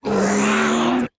evil-turkey-v2.ogg